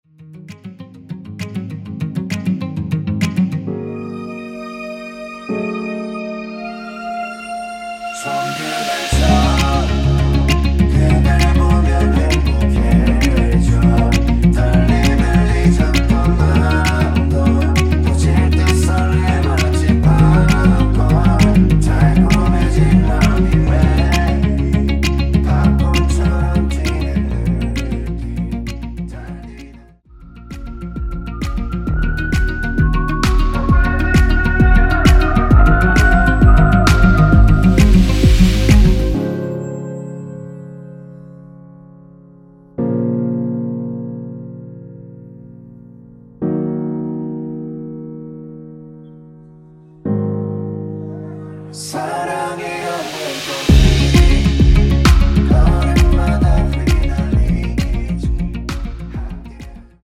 원키에서(-1)내린 코러스 포함된 MR입니다.
Db
앞부분30초, 뒷부분30초씩 편집해서 올려 드리고 있습니다.